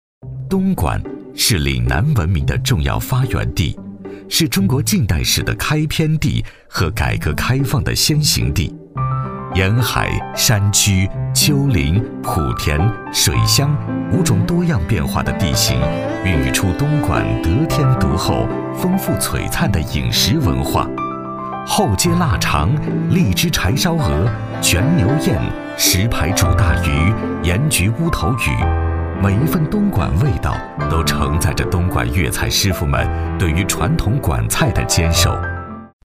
A男15号